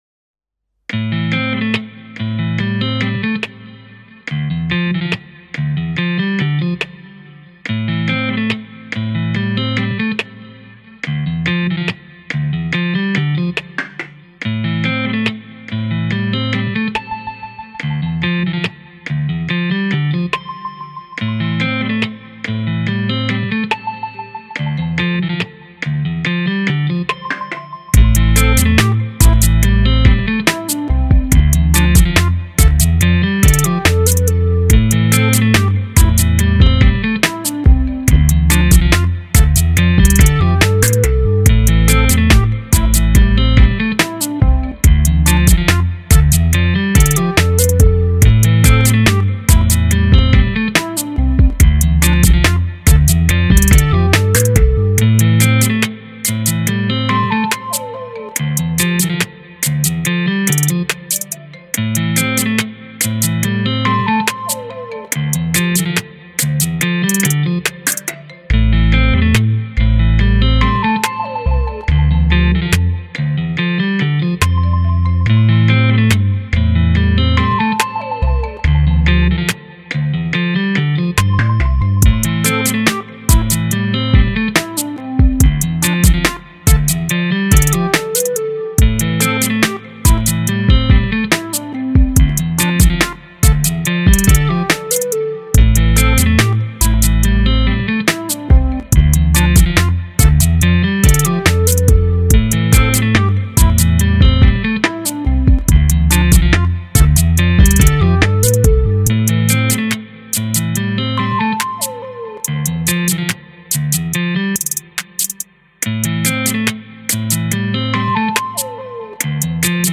Largo [0-10] mefiance - guitare electrique - - -